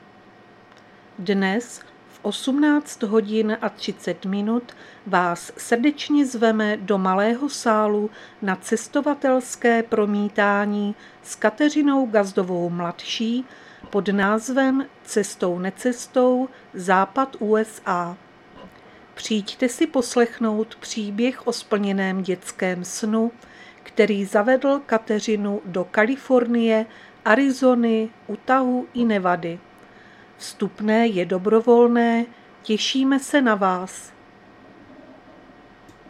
Záznam hlášení místního rozhlasu 23.4.2025
Zařazení: Rozhlas